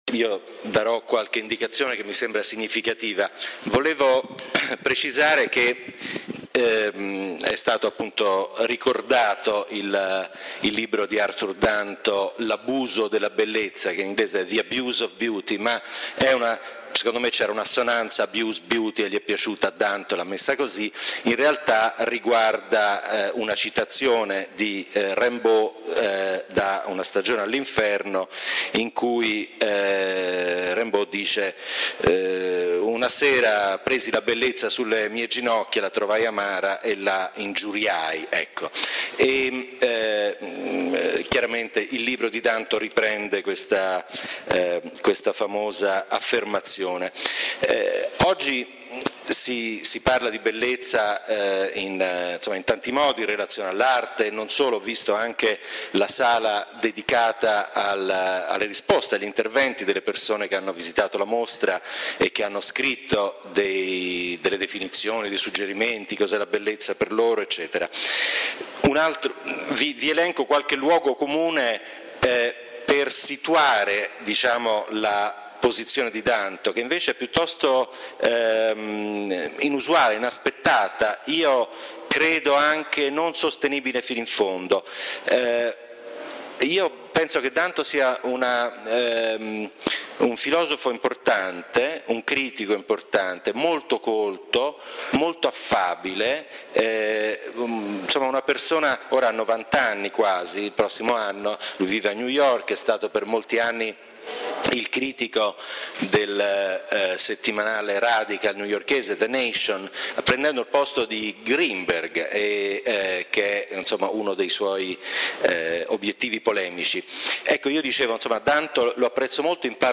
LECTURE / Beauty in Contemporary Art: Insults, Fetishisms, Misunderstandings